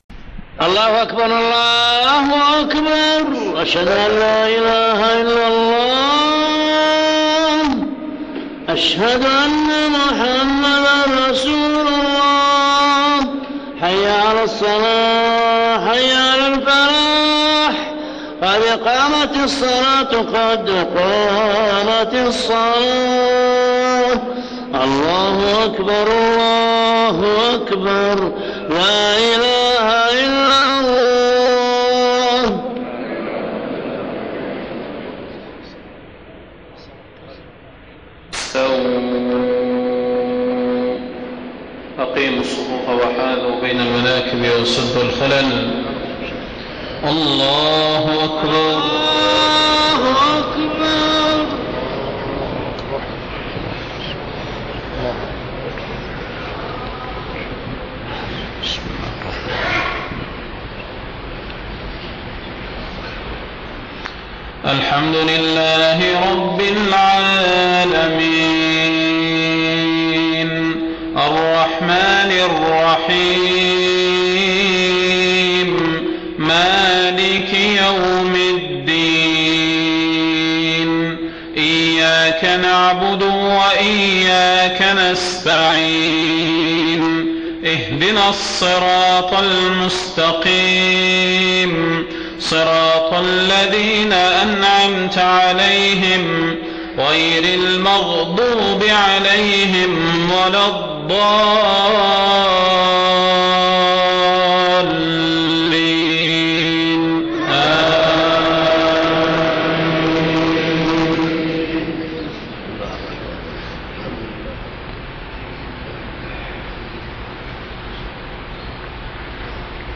صلاة المغرب 4 صفر 1430هـ سورتي الكافرون والإخلاص > 1430 🕌 > الفروض - تلاوات الحرمين